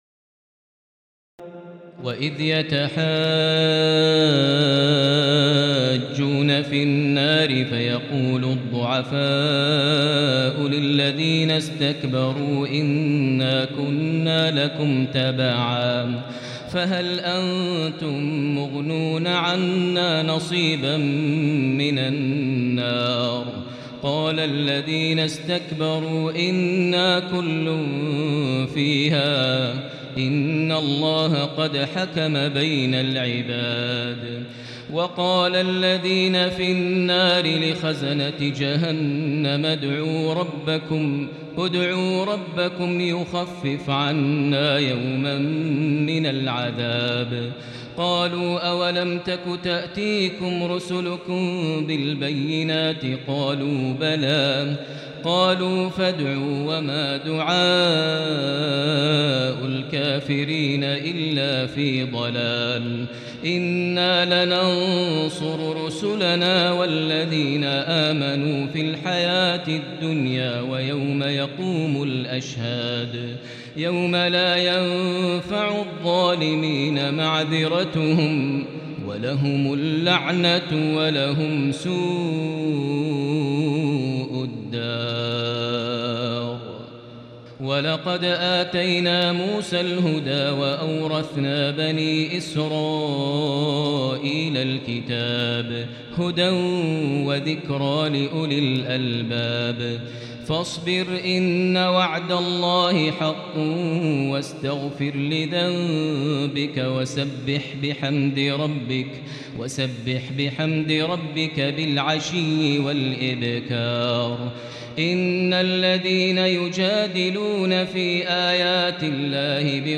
تراويح ليلة 23 رمضان 1438هـ من سور غافر (47-85) وفصلت (1-46) Taraweeh 23 st night Ramadan 1438H from Surah Ghaafir and Fussilat > تراويح الحرم المكي عام 1438 🕋 > التراويح - تلاوات الحرمين